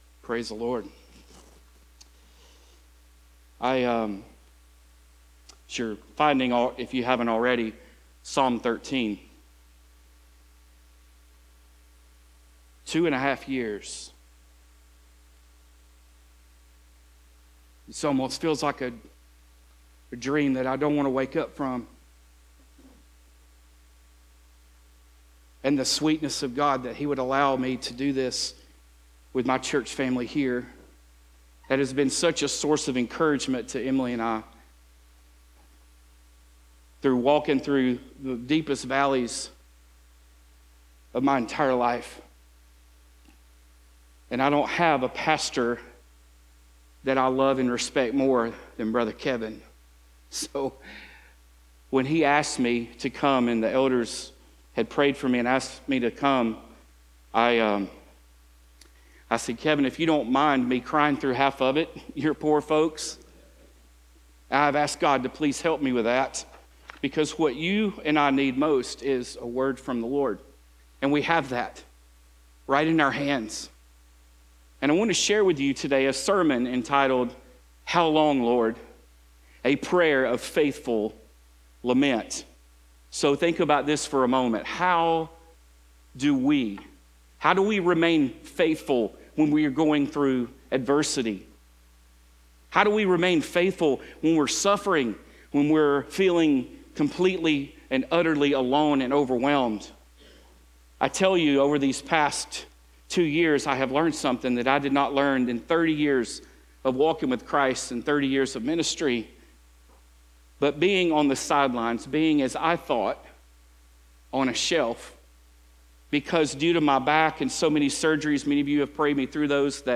These are sermons not associated with any particular sermon series.